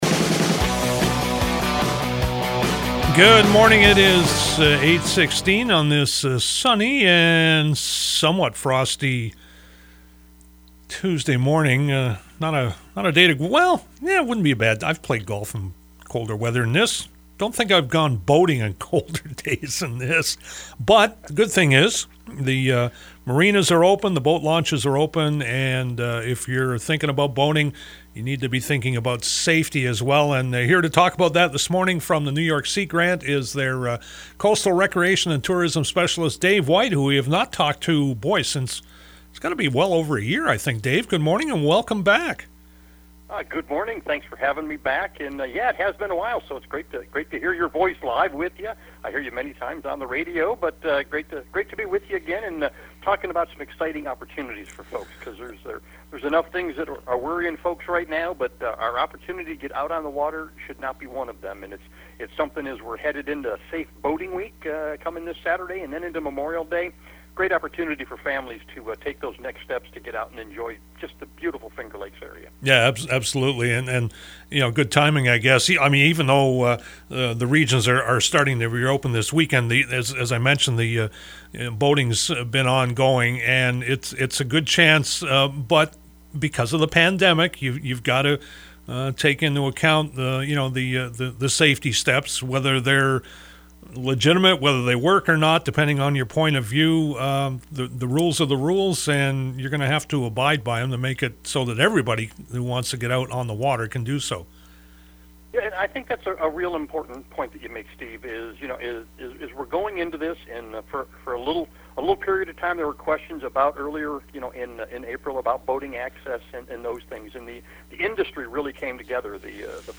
Two of those stations (WGVA and WAUB) comprise Finger Lakes News Radio, a CBS Radio News affiliate offering breaking news in the region 24 hours a day.